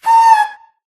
ghast
affectionate_scream.ogg